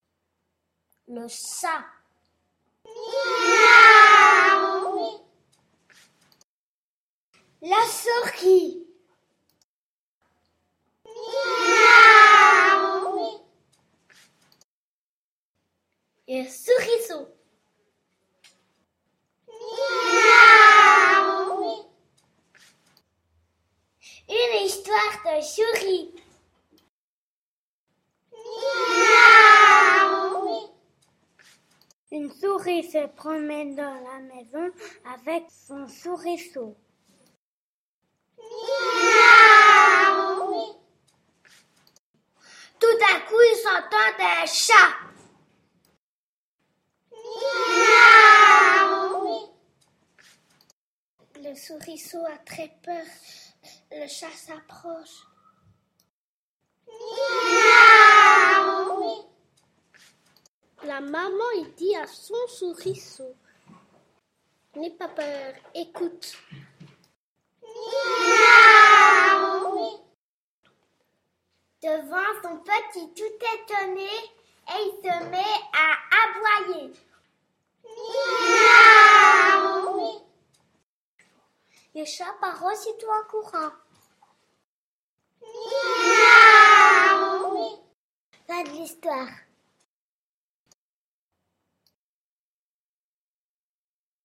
L’histoire de la souris racontée en français
Changer d’image à chaque miaou !